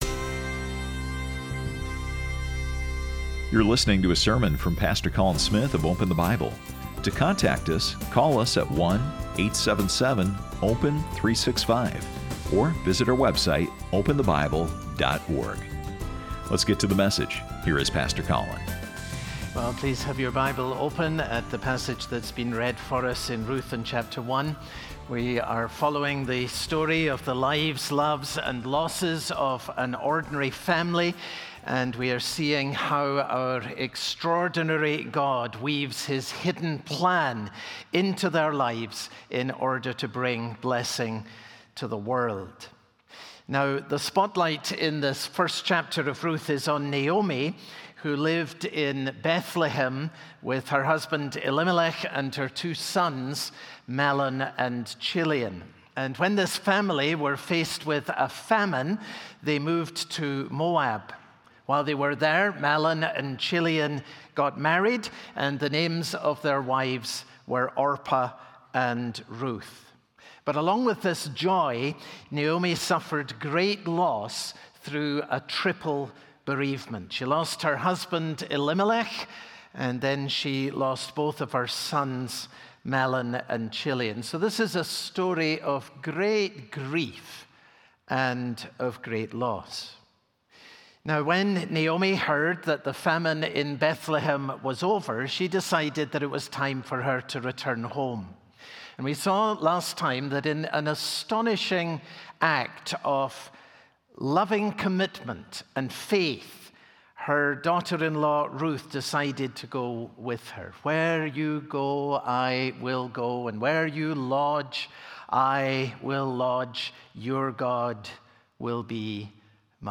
Sermon Details Date Sep 14